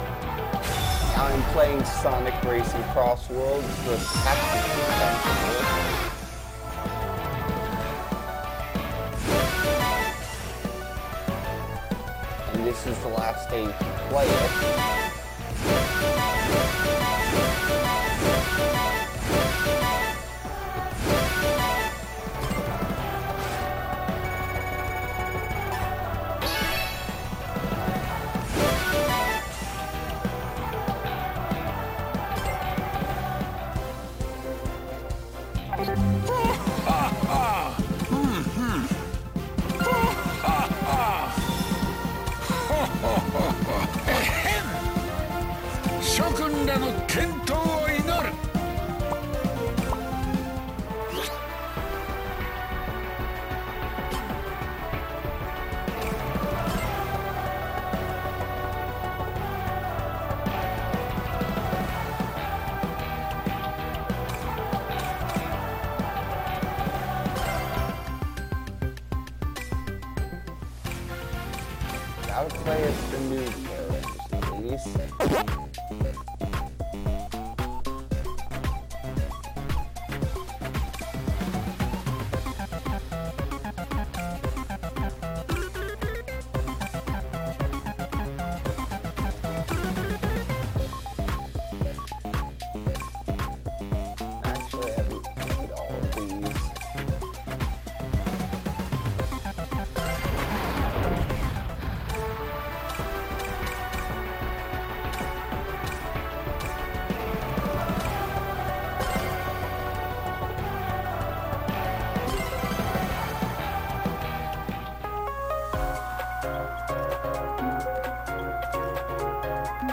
I play Sonic Racing Crossworlds with commentary